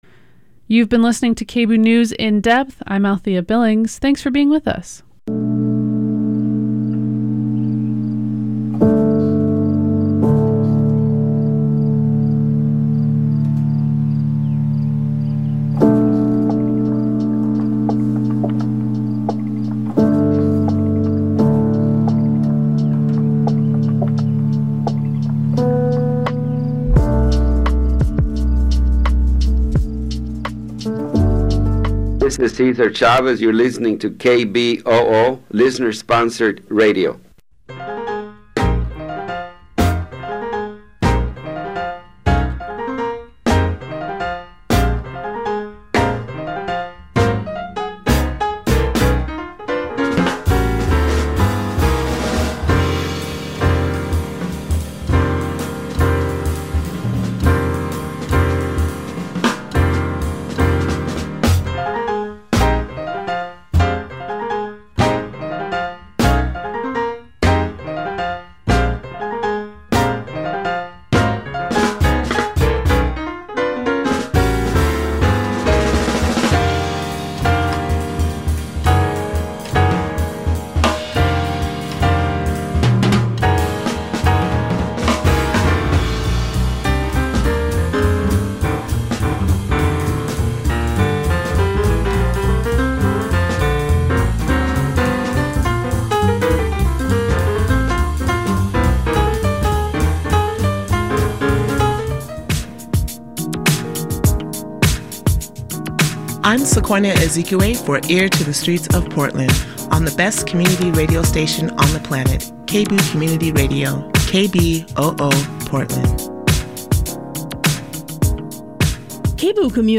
This Way Out is the only internationally distributed weekly LGBTQ radio program, currently airing on some 200 local community radio stations around the world. The award-winning half-hour magazine-style program features a summary of some of the major news events in or affecting the queer community (NewsWrap), in-depth coverage of major events, interviews with key queer figures, plus music, literature, entertainment — all the information and culture of a community on the move!